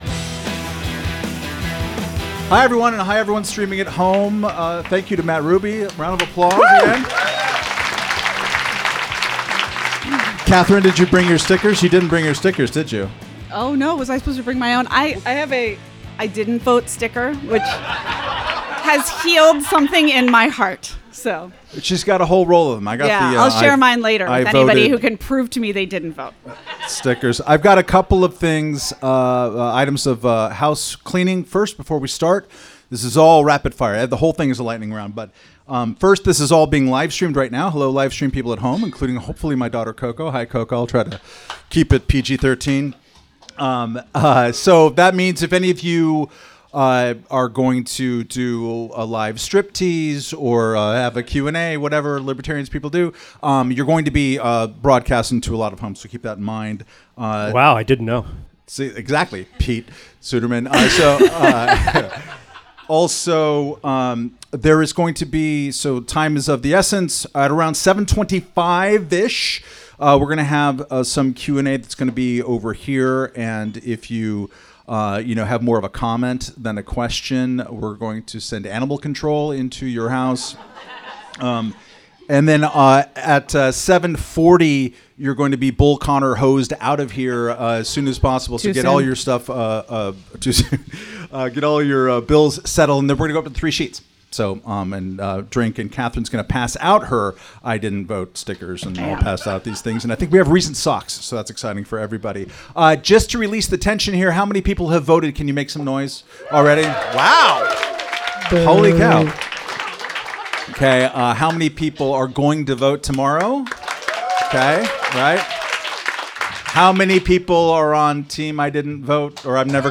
Tune in on November 4 to hear the four co-hosts' unflinching critiques of the latest in politics, culture, and whatever fresh hell awaits us all.